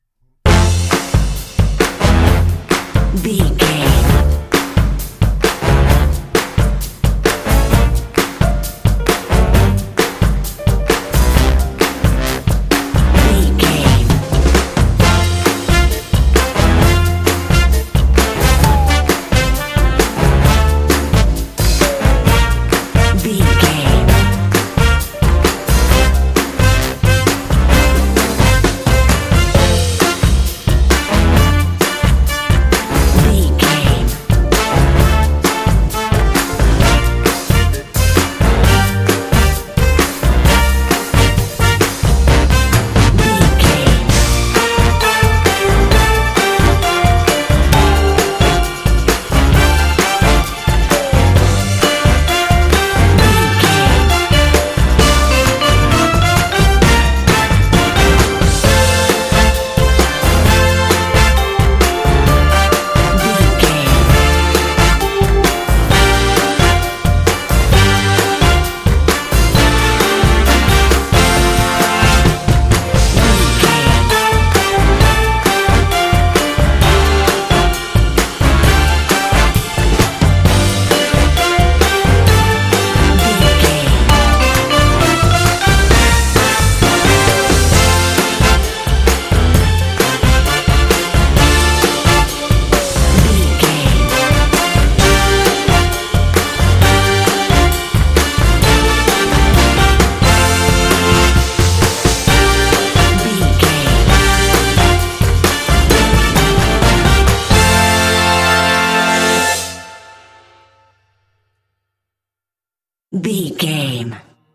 Uplifting
Ionian/Major
happy
bouncy
groovy
drums
brass
electric guitar
bass guitar
strings
rock and roll